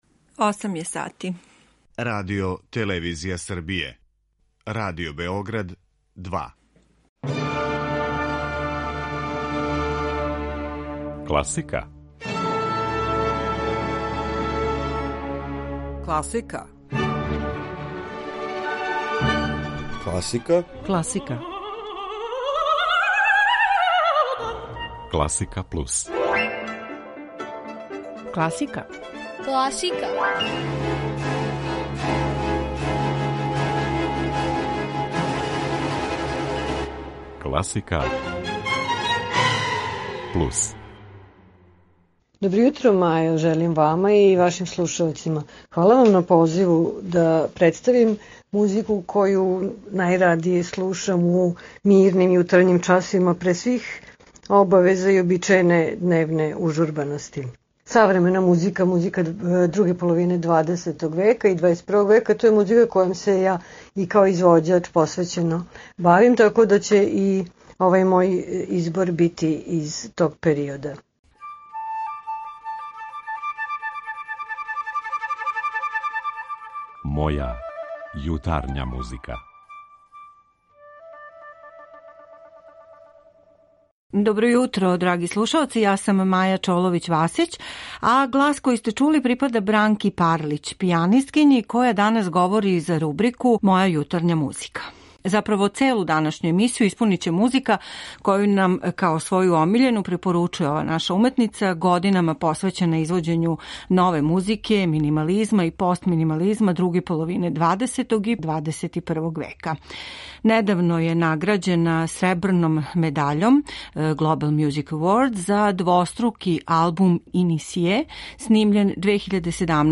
Емисију ће испунити несвакидашњи и сасвим оригинални избор нове музике, којој је ова наша угледна пијанисткиња посвећена као интепретатор и промотер већ деценијама. Слушаћете медитативне композиције, дела необичног споја традиције и експеримента, звуке необичних састава и музичких инструмената из опуса америчких аутора, минималиста Филипа Гласа I Џона Адамса, као и Чарсла Ајвза, једног од најзначајнијих аутора прве половине XX века.